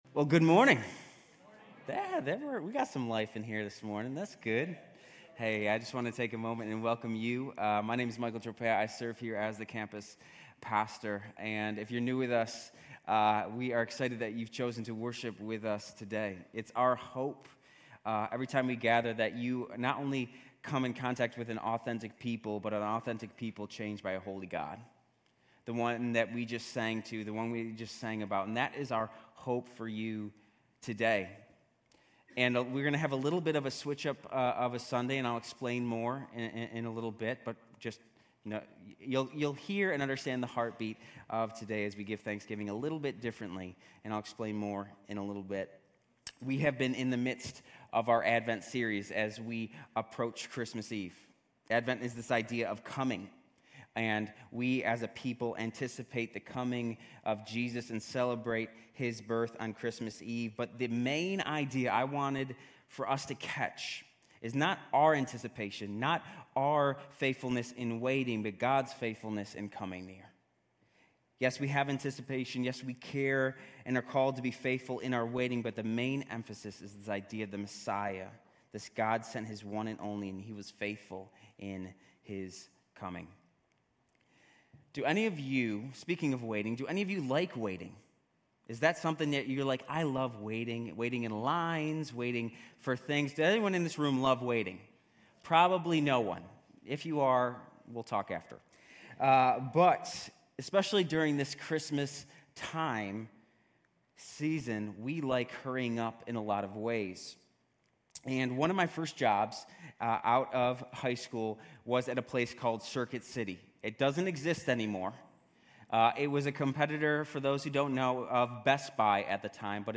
GCC-UB-Dec-17-Sermon.mp3